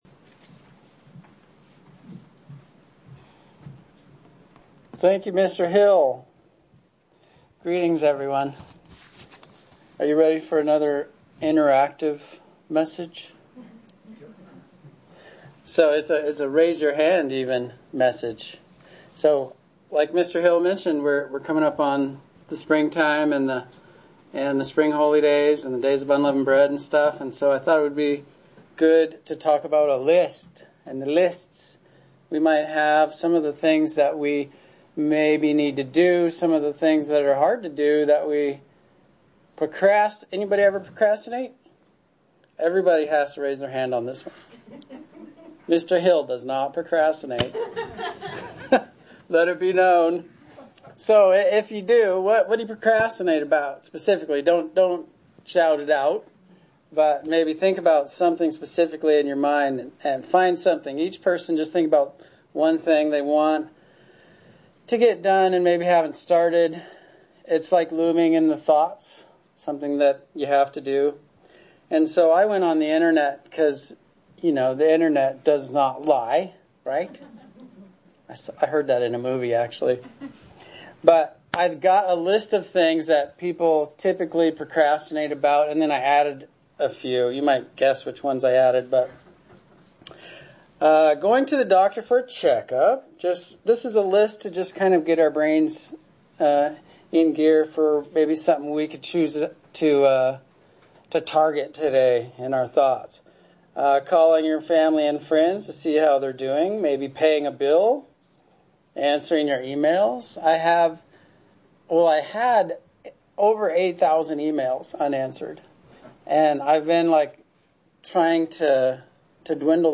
In today's sermon we will talk about getting our priorities in order. All of us want to be productive but knowing what we need most to do is so important.